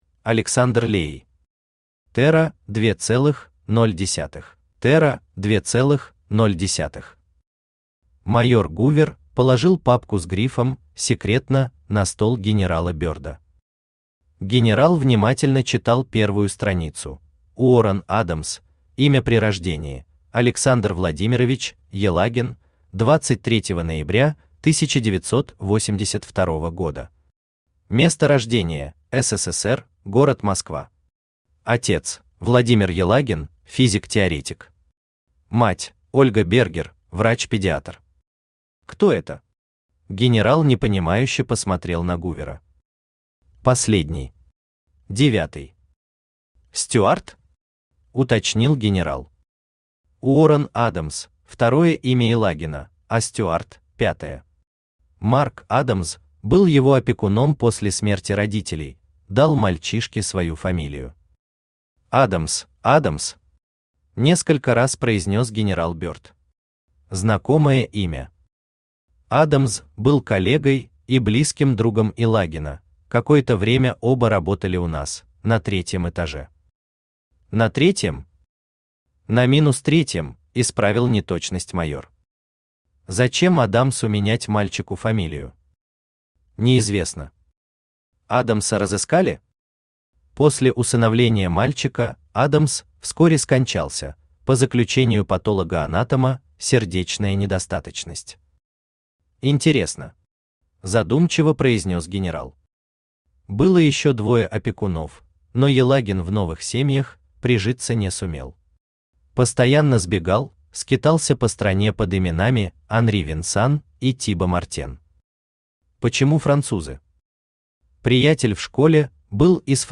Аудиокнига Терра 2.0 | Библиотека аудиокниг
Aудиокнига Терра 2.0 Автор Александр Леей Читает аудиокнигу Авточтец ЛитРес.